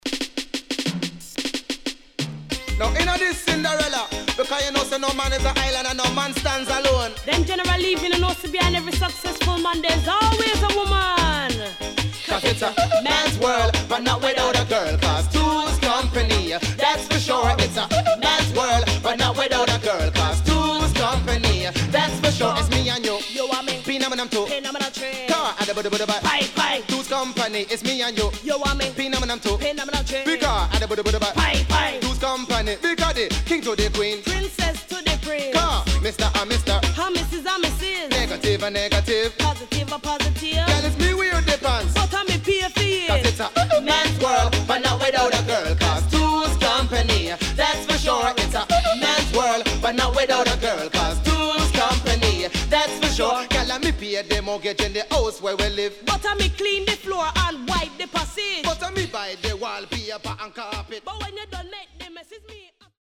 Rare.Wicked Combination UK Dancehall
SIDE A:少しチリノイズ入りますが良好です。